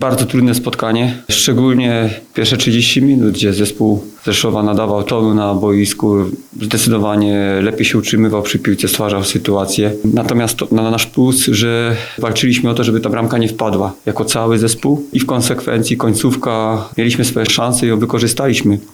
Trener 'Słoni’ Marcin Brosz przyznał podczas pomeczowej konferencji prasowej, że spotkanie nie należało do najłatwiejszych.